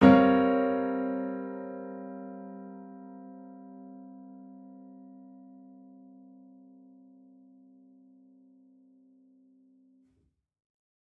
Index of /musicradar/gangster-sting-samples/Chord Hits/Piano
GS_PiChrd-Gmin7+9.wav